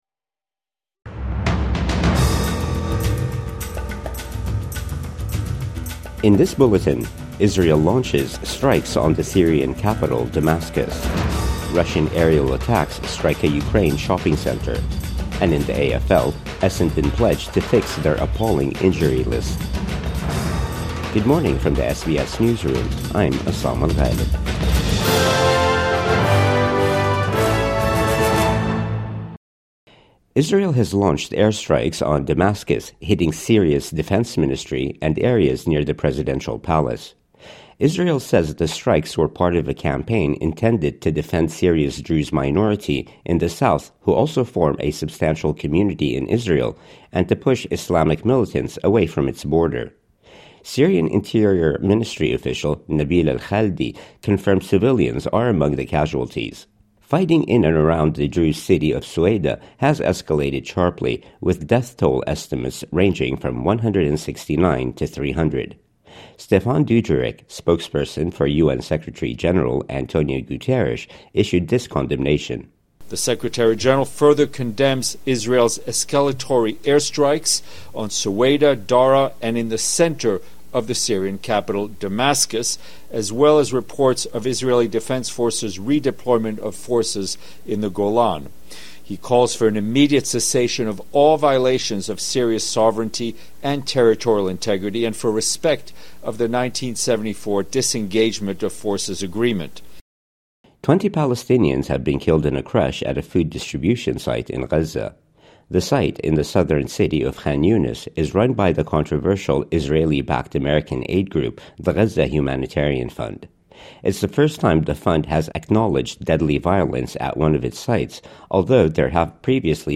Israel launches strikes on the Syrian capital, Damascus | Morning News Bulletin 17 July 2025